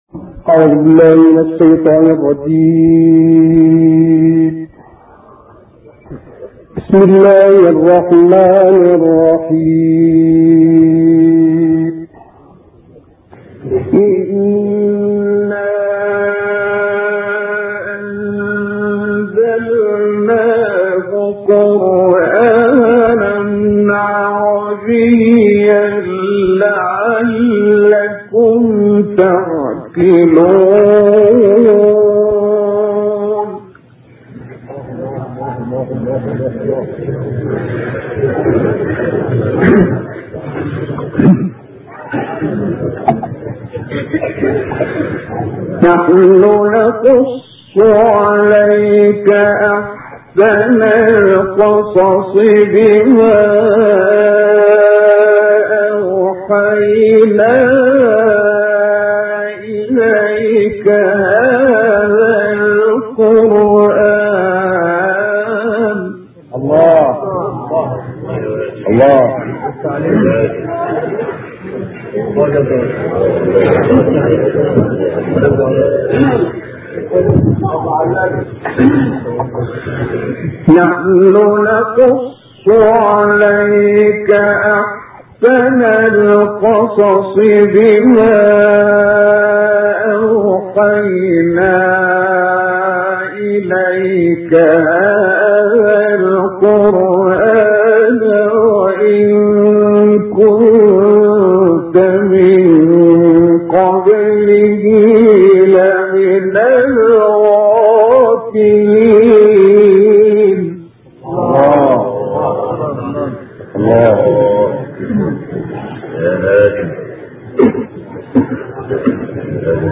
সুললিত কণ্ঠে সূরা ইউসুফ তিলাওয়াত